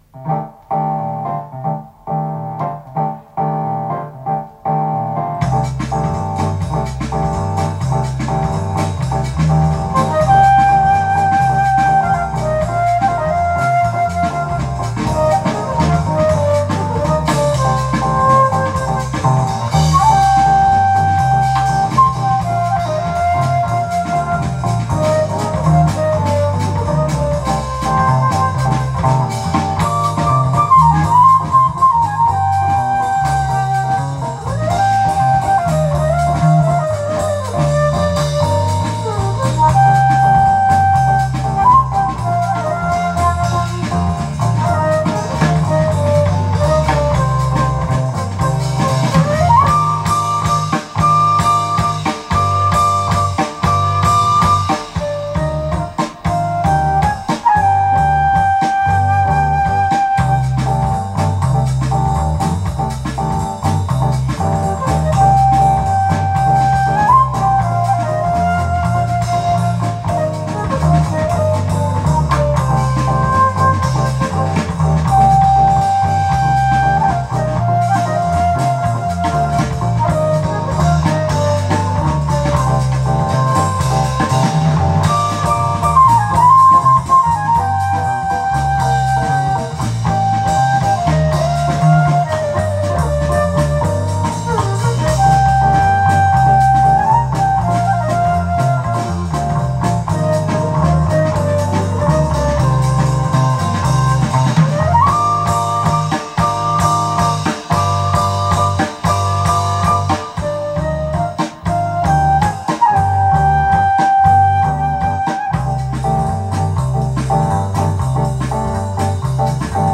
レア・オーストラリアン・ジャズ！！！
ムーディーなイントロで幕を開けダンサブルなサンバジャズ的に展開する極上スキャット・ジャズ